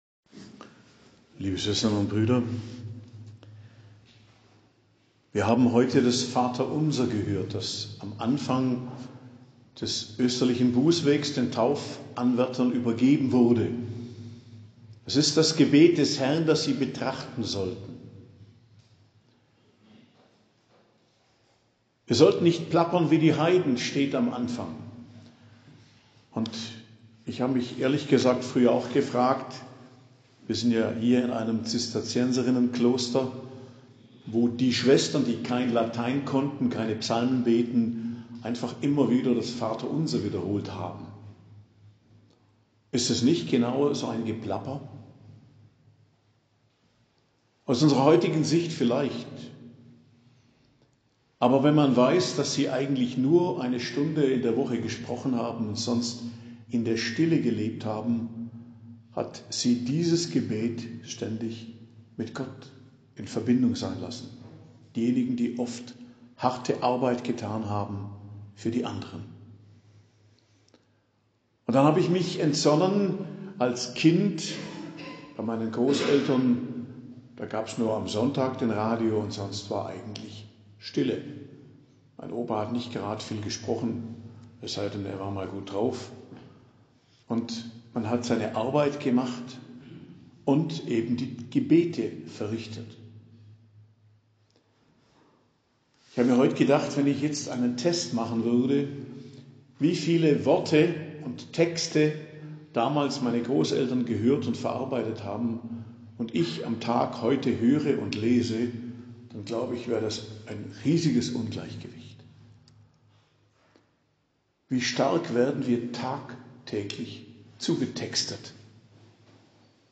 Predigt am Dienstag der 1. Woche der Fastenzeit, 20.02.2024